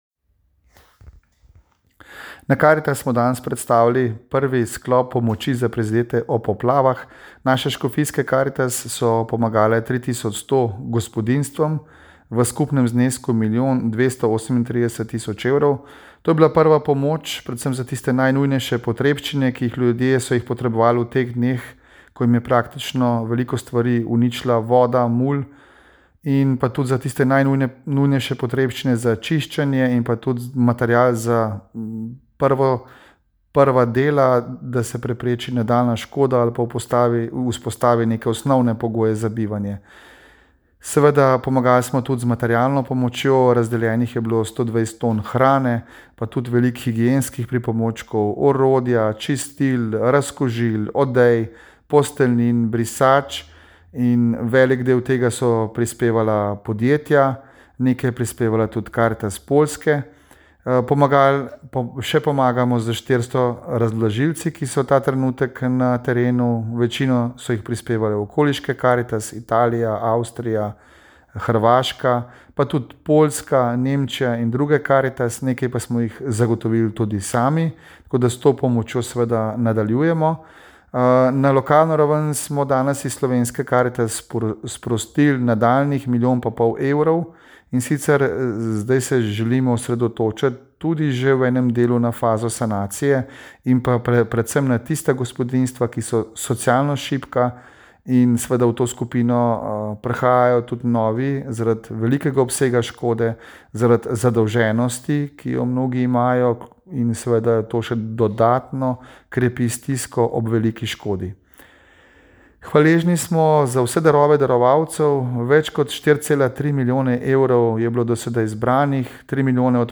Tiskovna konferenca Slovenske karitas Foto: Slovenska karitas
ZVOČNI POSNETEK IZJAVE